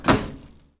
Foleys " 微波炉门关闭
描述：用V67录制